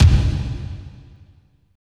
35.08 KICK.wav